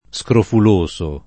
scrofoloso [ S krofol 1S o ]